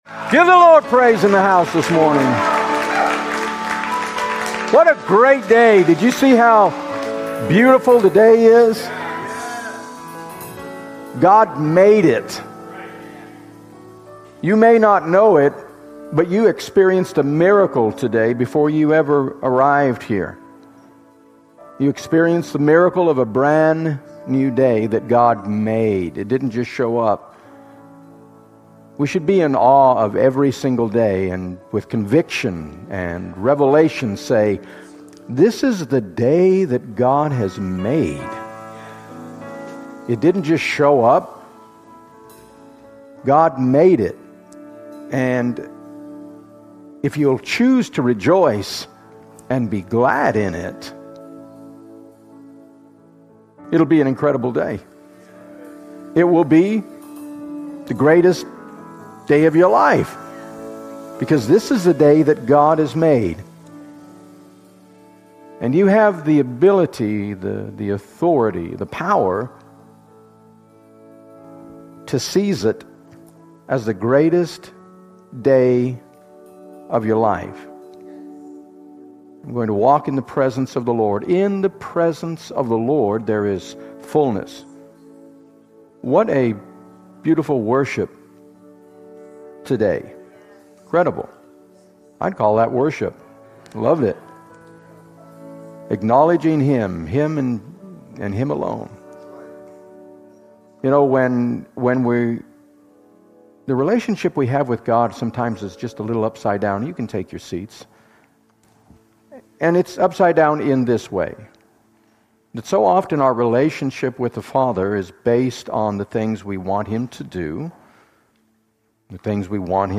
Sermon: Full Service: